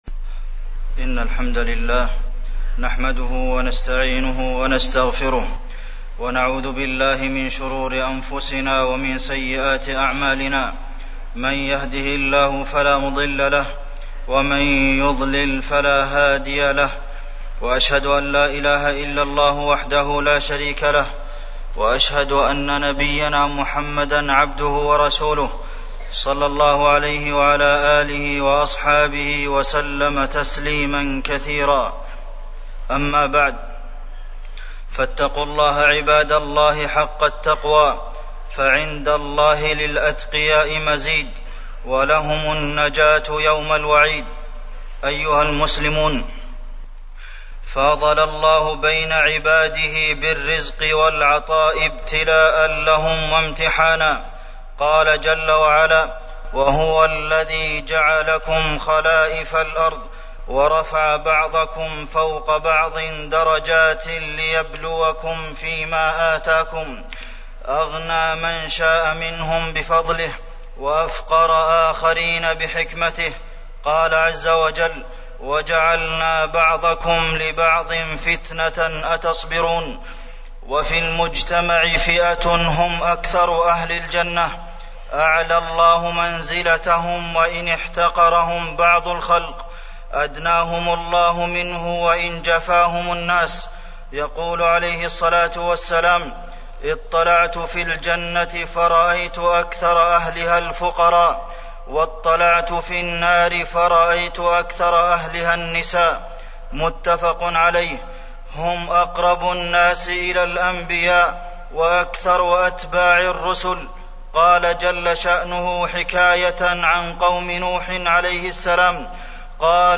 تاريخ النشر ١٢ شعبان ١٤٢٦ هـ المكان: المسجد النبوي الشيخ: فضيلة الشيخ د. عبدالمحسن بن محمد القاسم فضيلة الشيخ د. عبدالمحسن بن محمد القاسم أحوال الفقراء والصدقة